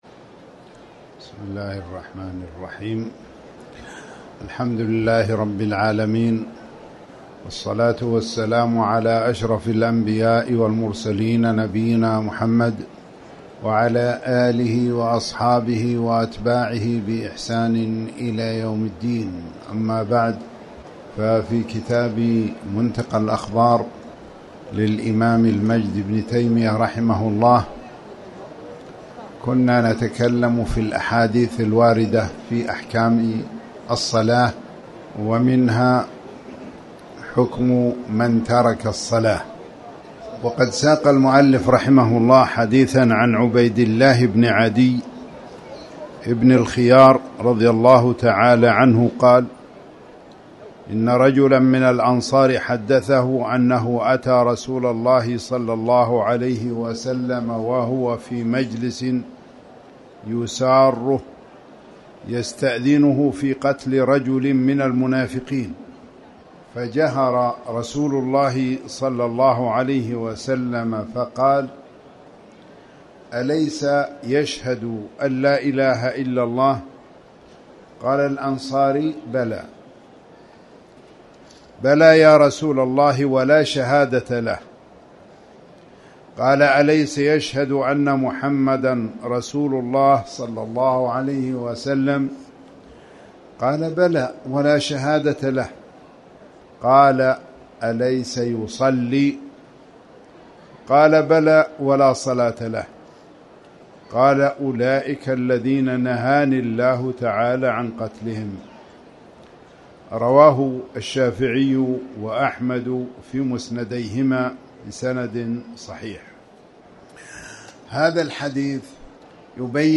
تاريخ النشر ٢٢ شعبان ١٤٣٩ هـ المكان: المسجد الحرام الشيخ